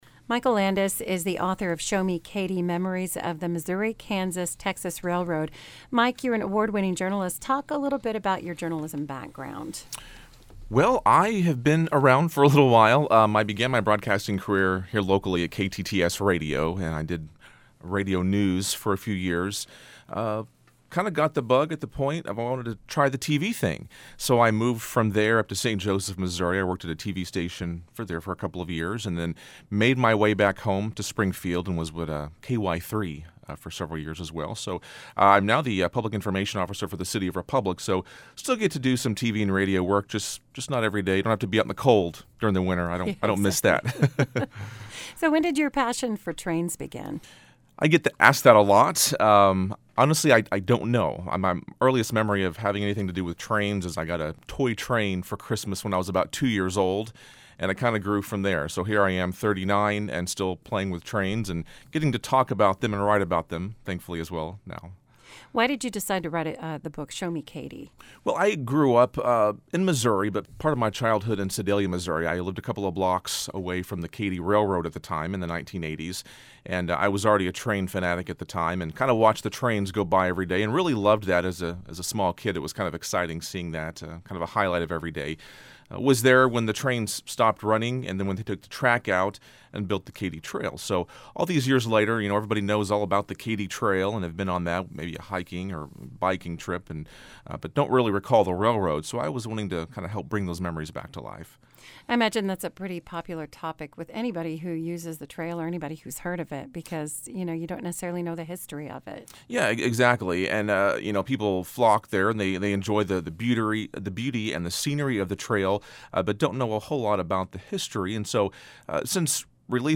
An Interview With The Author